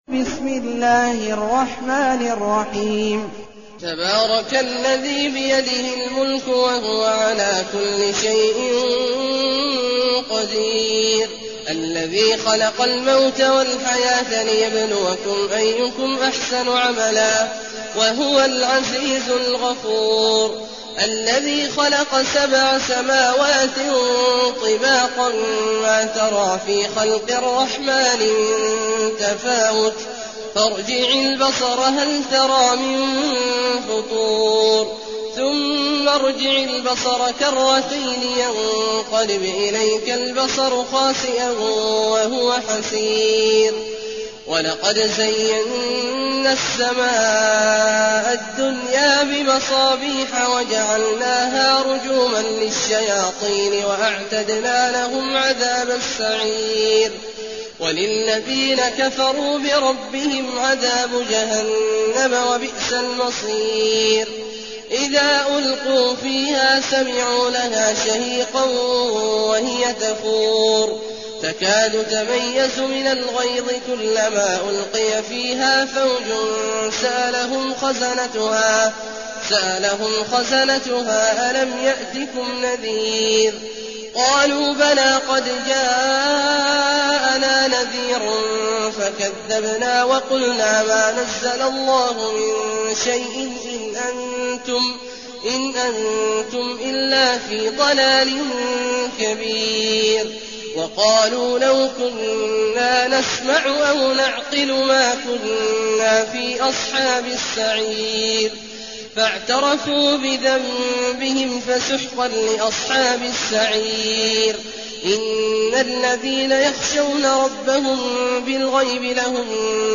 المكان: المسجد الحرام الشيخ: عبد الله عواد الجهني عبد الله عواد الجهني الملك